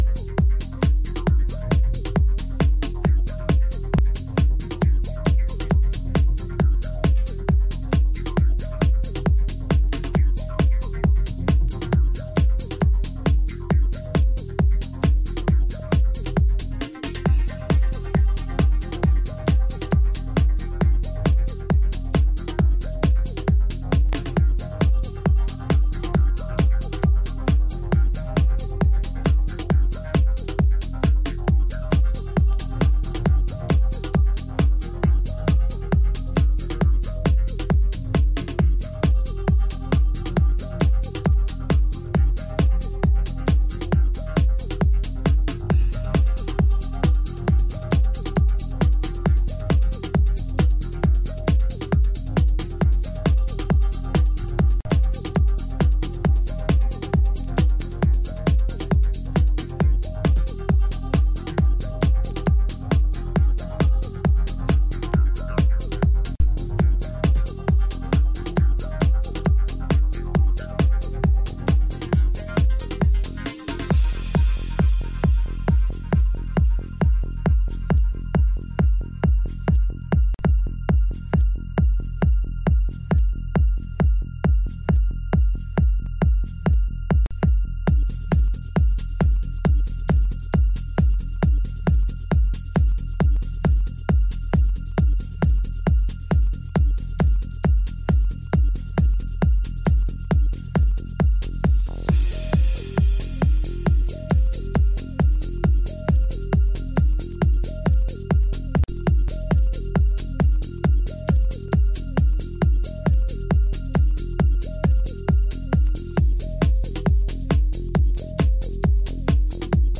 軽快なアップテンポのハウス
やはり今回も90’s IDMの精神性を感じさせる美的センスが横溢。